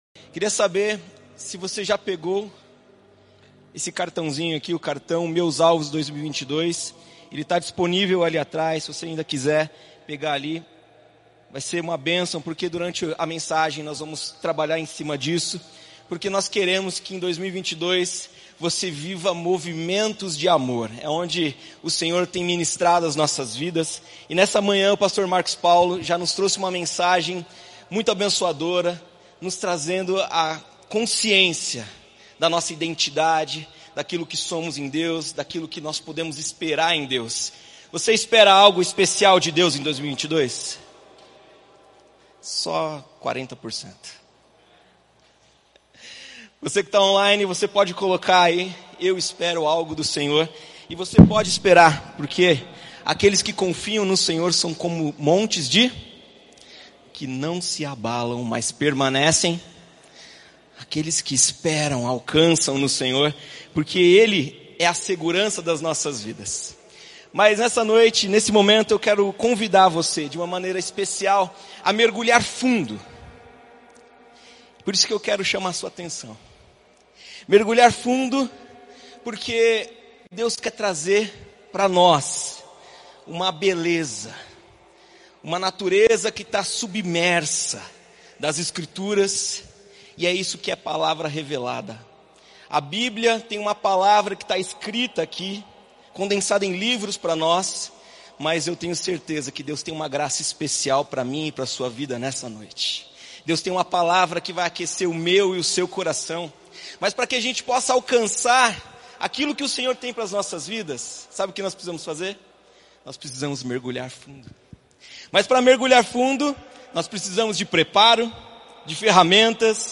Igreja Batista do Bacacheri